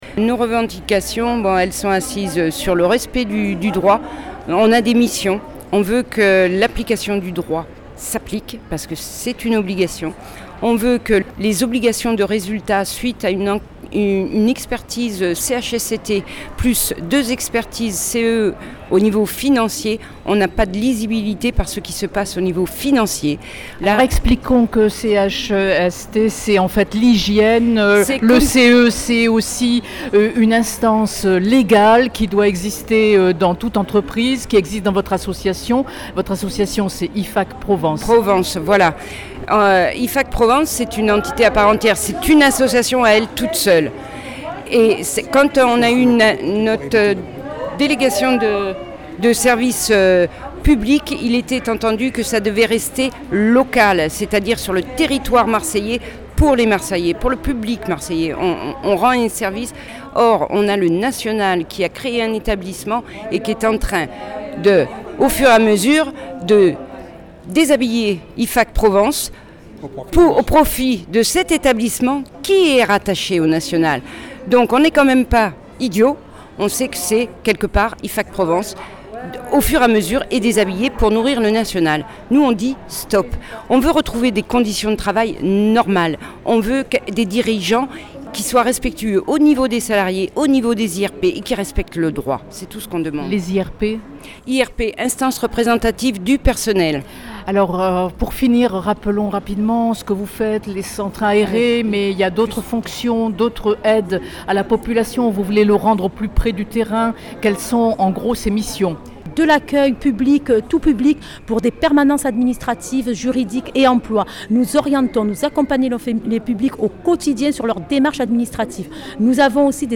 Reportage son